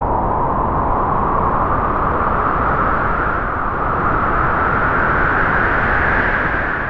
wind (1)